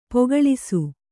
♪ pogaḷisu